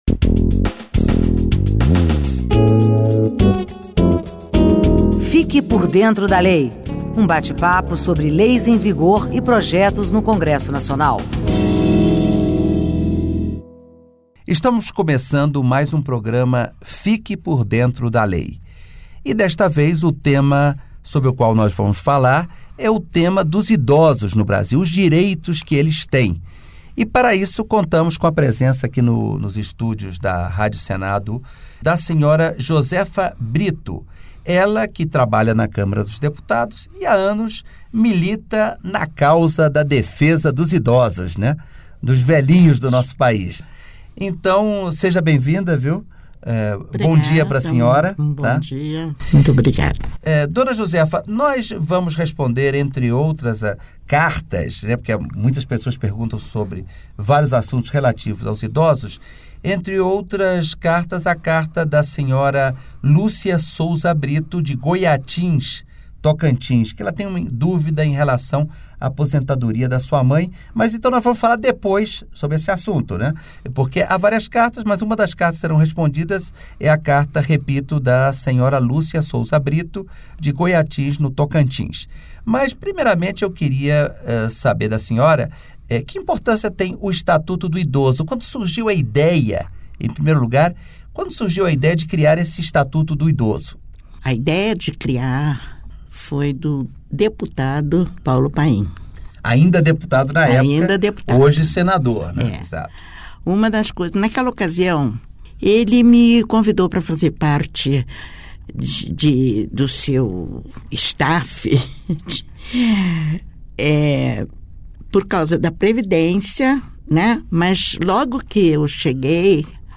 Entrevista com servidora da Câmara dos Deputados